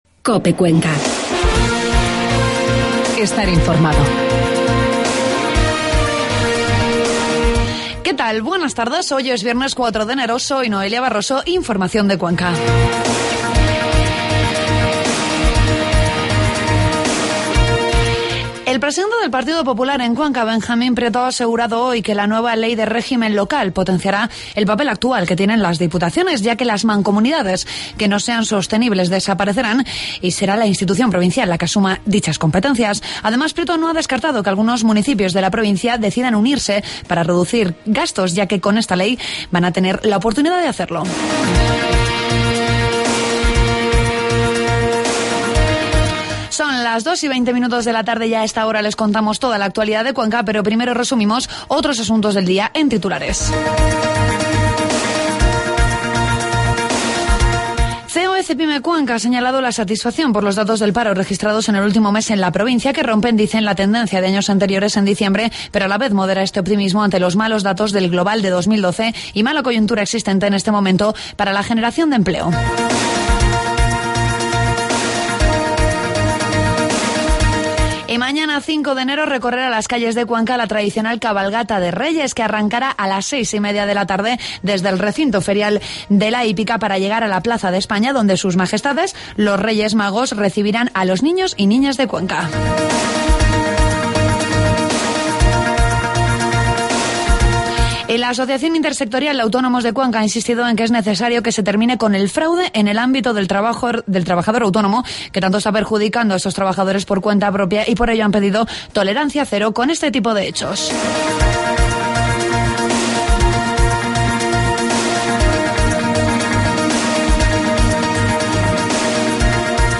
Toda la información de la provincia de Cuenca en los informativos de Mediodía de COPE